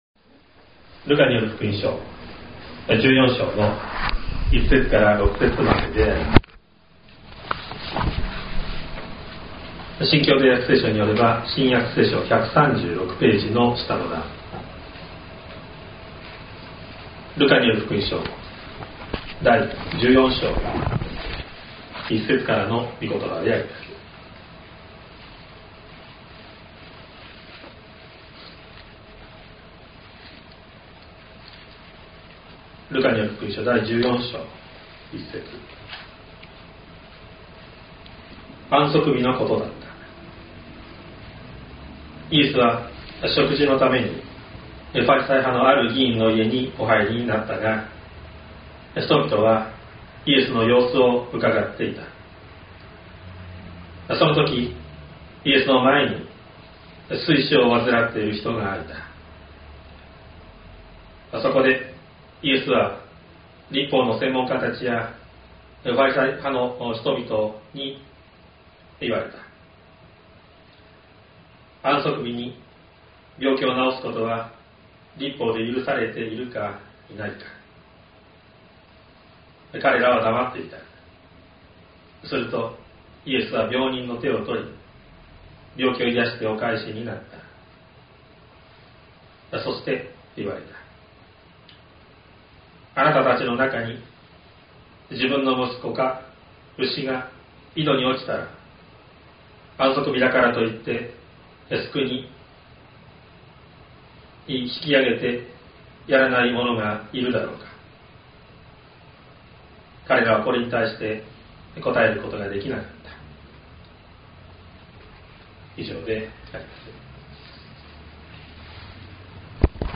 2025年01月05日朝の礼拝「イエスの様子をうかがう人々」西谷教会
説教アーカイブ。
音声ファイル 礼拝説教を録音した音声ファイルを公開しています。